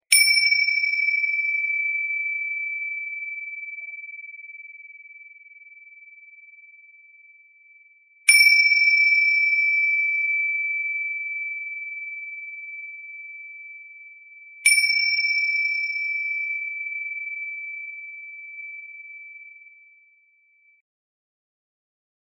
ティンシャは、手で揺らしたり、軽く叩いたりして音を奏でます。
ティンシャ(チベタンベル)３回 着信音